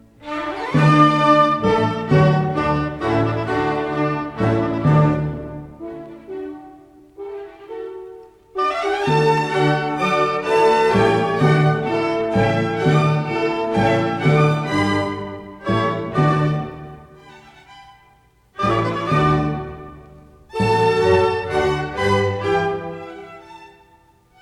Menuet and Trio